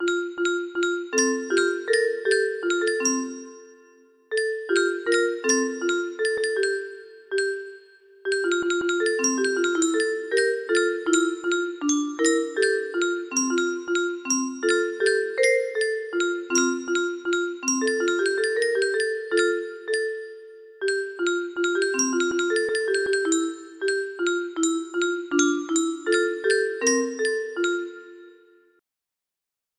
Clone of Unknown Artist - Untitled music box melody
Grand Illusions 30 (F scale)